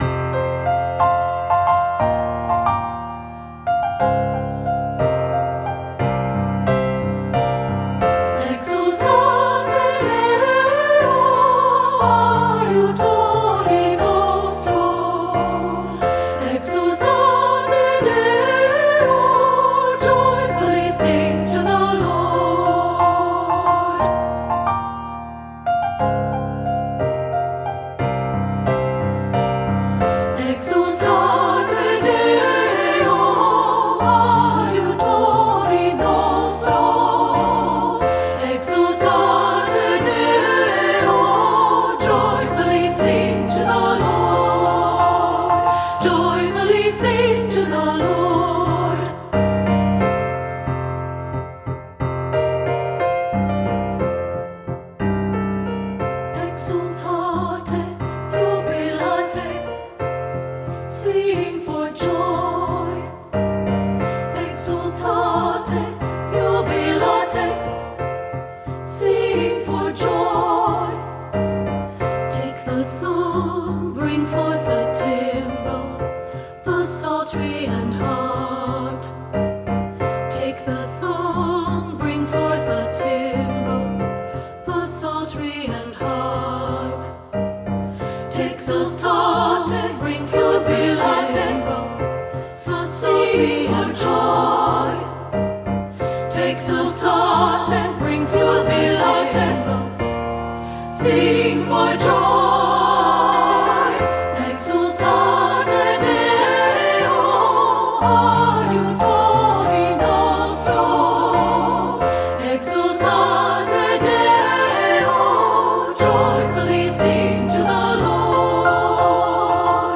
• Soprano 1
• Soprano 2
• Alto
• Piano
Studio Recording
Full of energy, rhythmic twists, and fun harmonies
Ensemble: Treble Chorus
Key: F major
Tempo: With energy (q. = 60)
Accompanied: Accompanied Chorus